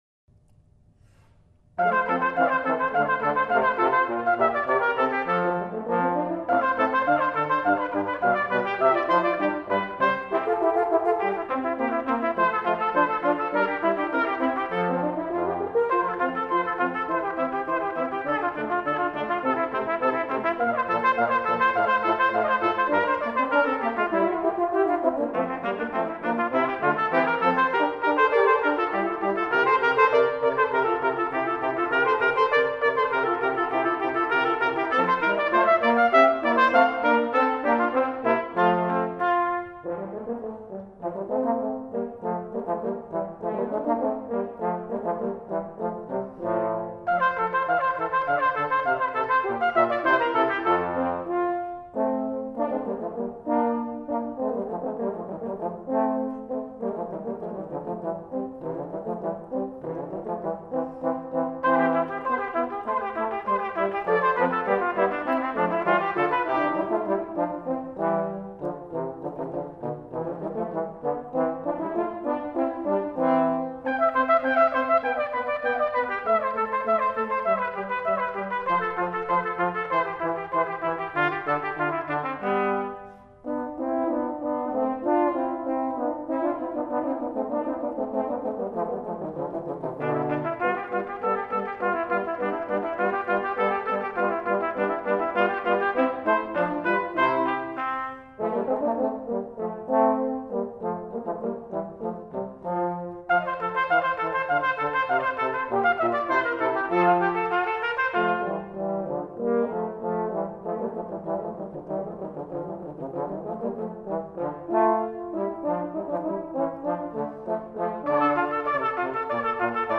trumpet
trombone.
Very nice – but challenging – arrangement